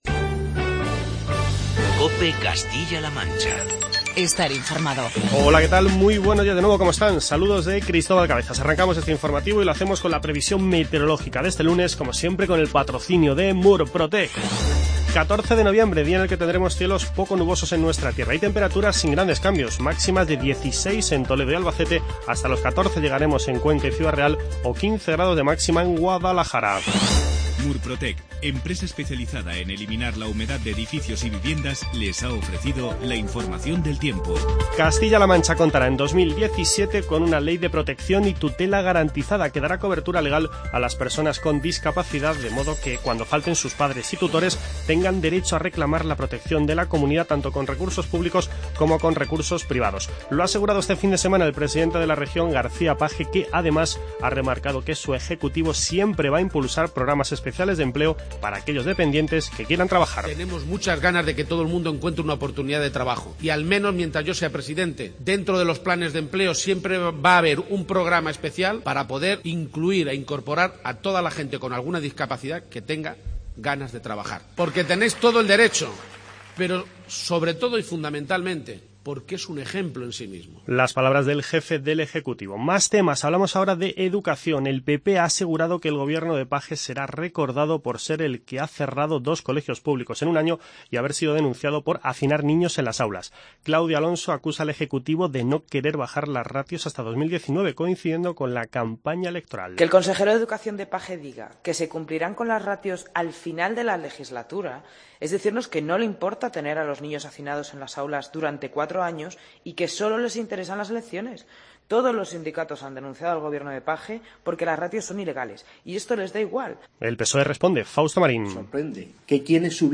Informativo COPE Castilla-La Mancha